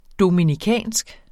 Udtale [ dominiˈkæˀnsg ]